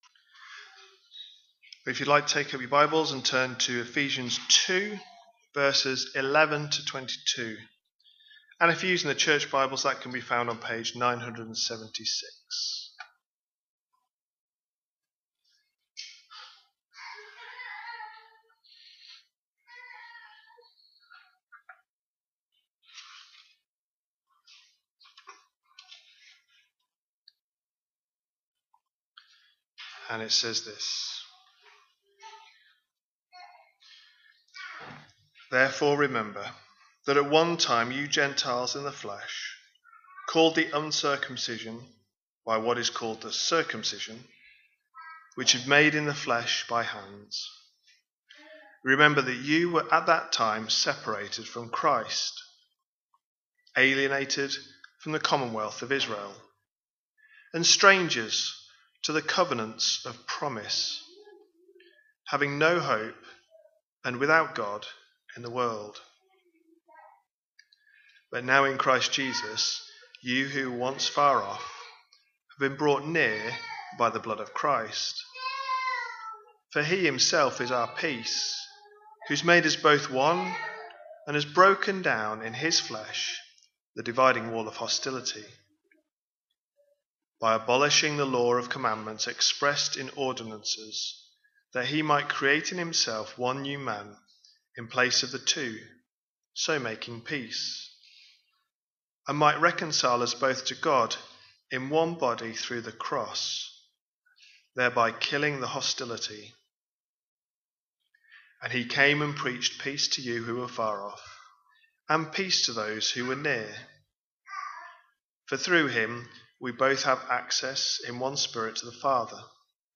A sermon preached on 5th April, 2026, as part of our Ephesians 2026 series.